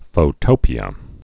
(fō-tōpē-ə)